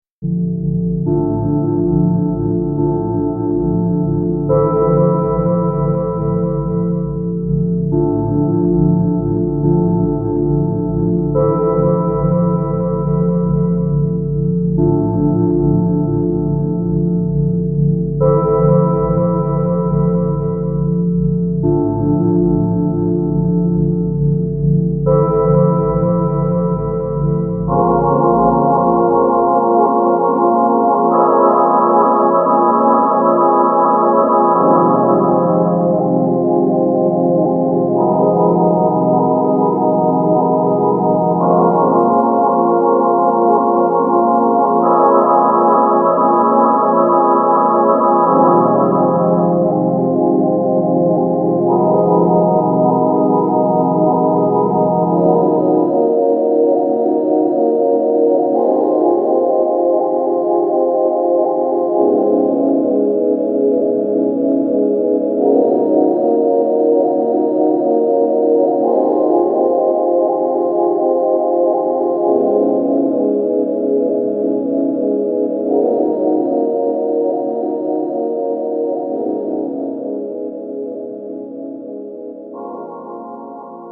White_noiz.mp3